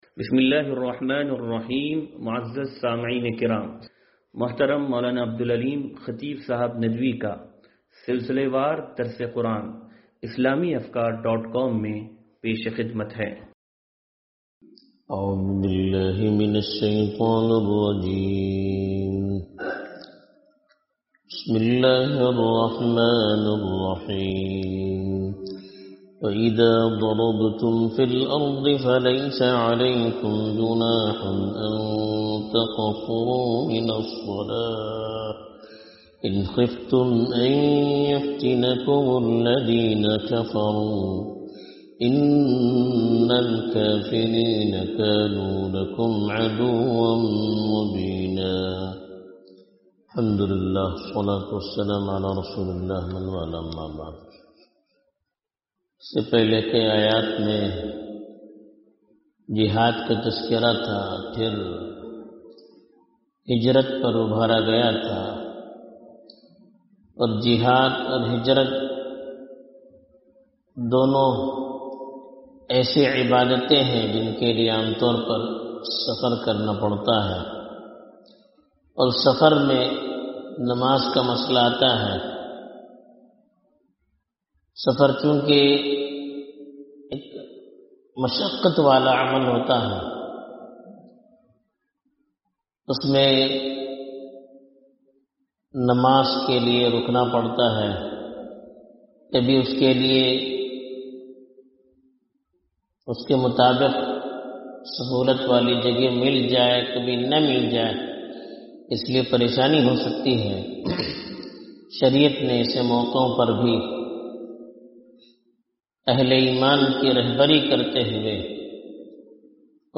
درس قرآن نمبر 0385